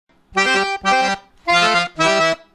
Triplets and trills
The Cajun Box trill is actually 3 notes played in rapid succession .. usually so quickly that they sort of blend together.
But, you can hear that something fancy was added and that the melody becomes more "spiced-up"!
trill.wma